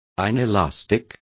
Complete with pronunciation of the translation of inelastic.